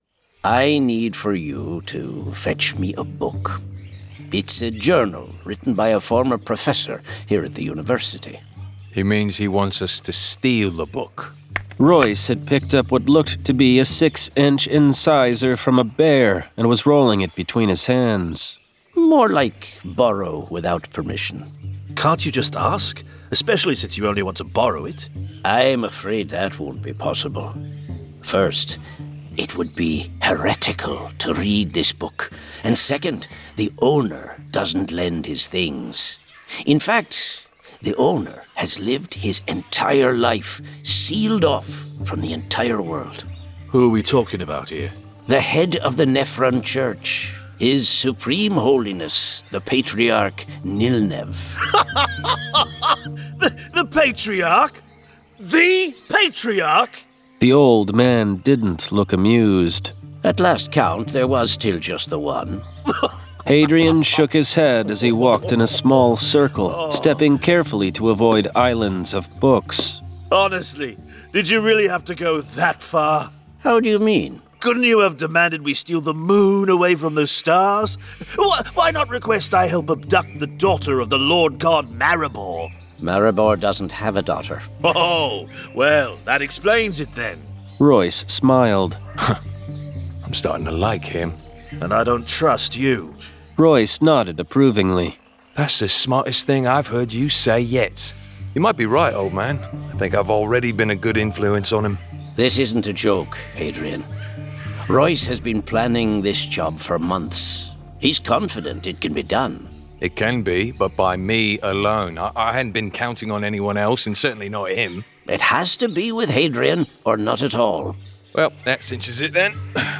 Full Cast. Cinematic Music. Sound Effects.
Genre: Fantasy